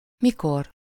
Ääntäminen
IPA : /ˈwɛn/